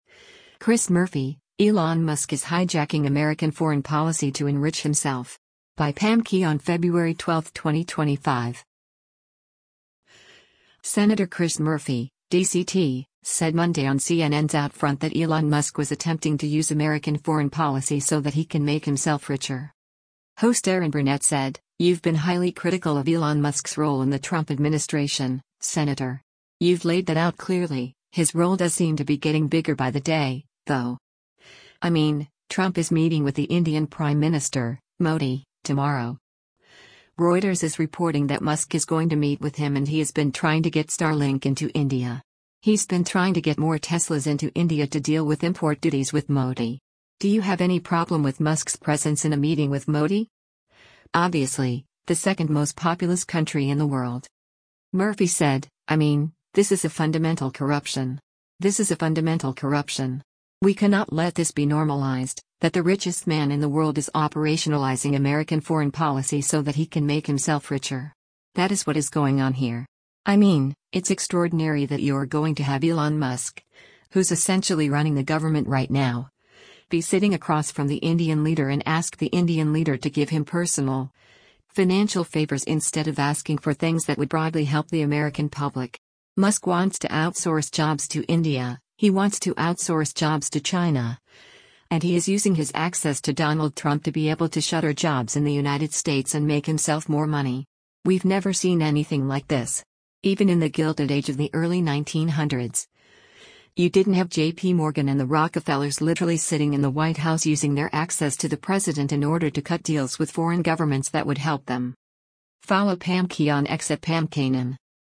Senator Chris Murphy (D-CT) said Monday on CNN’s “OutFront” that Elon Musk was attempting to use American foreign policy “so that he can make himself richer.”